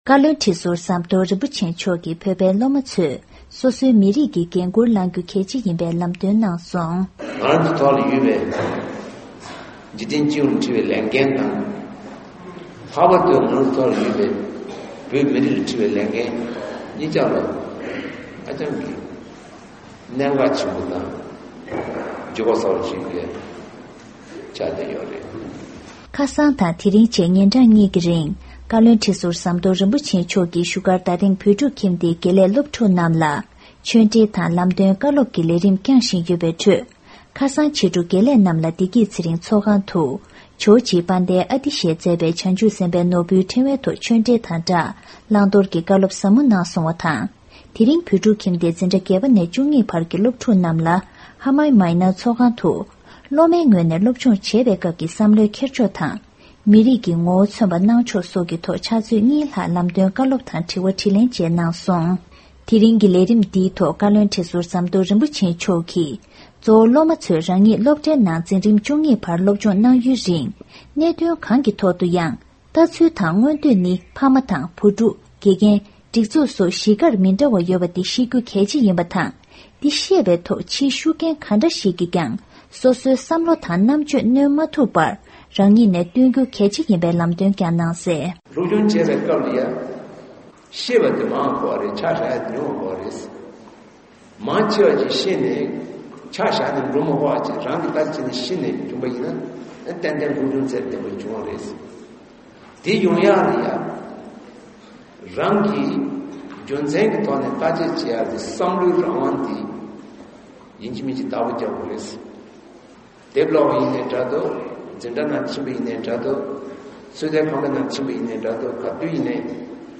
བཀའ་བློན་ཁྲི་ཟུར་ཟམ་གདོང་རིན་པོ་ཆེ་མཆོག་གིས་གསུང་བཤད།
བཀའ་ཟུར་མཆོག་གིས་གསུང་བཤད།